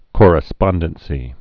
(kôrĭ-spŏndən-sē, kŏr-)